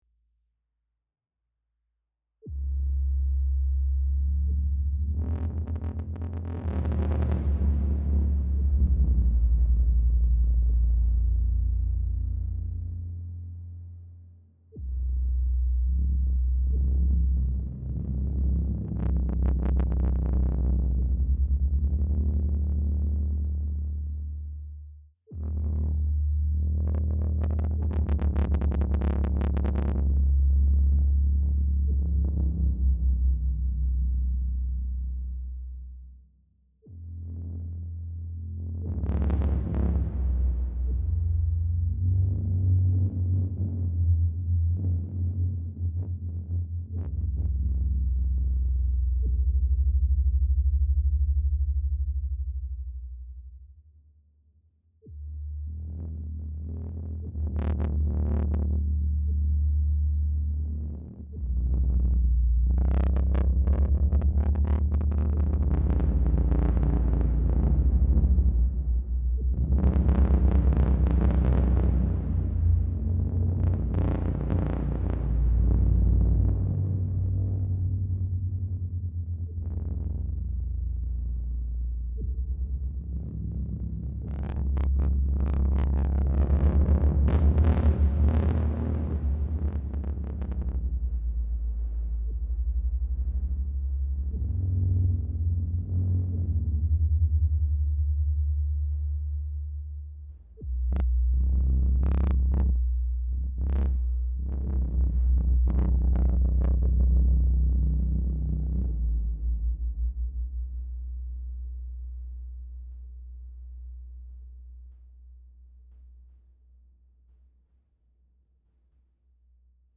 Somehow today's creative session got way shorter than anticipated, but I did manage to tweak a bit of sub-bass goodness in 22-tone scale.